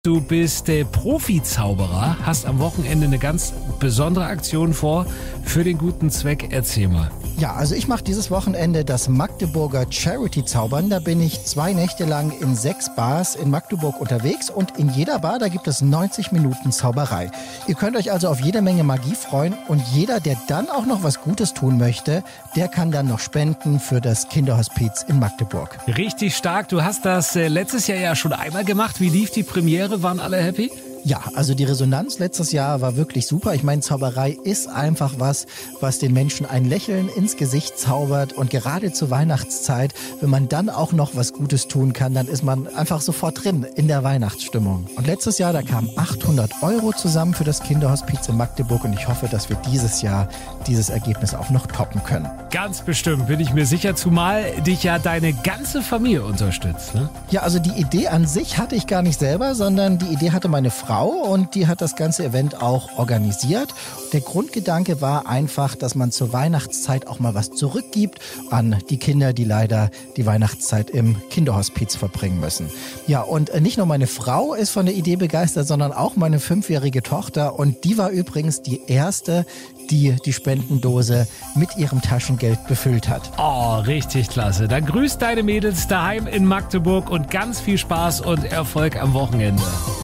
Radiointerview-mdr-jump.mp3